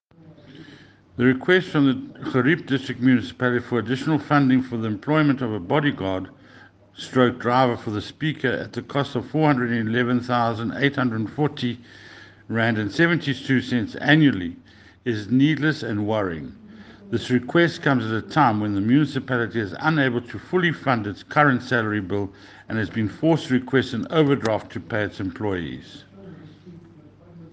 English soundbite by Cllr Ian Riddle.